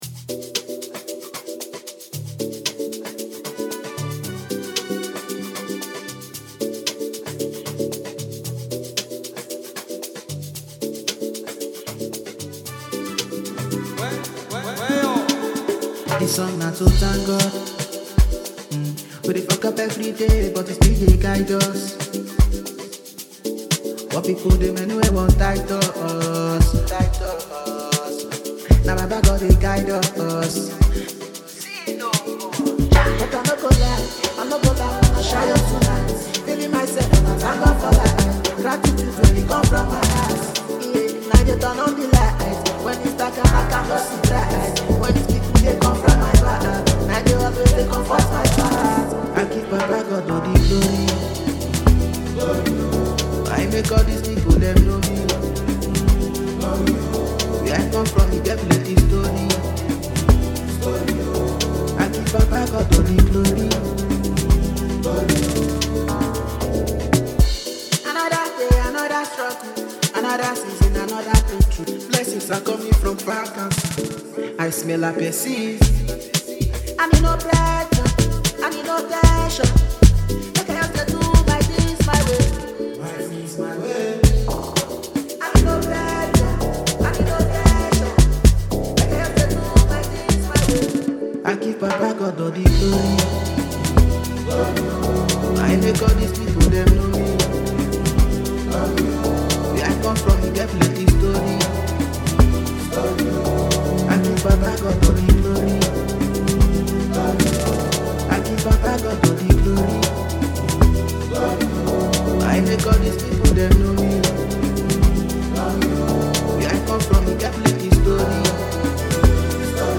motivational appreciation song
Download enjoy ad share this new street banga.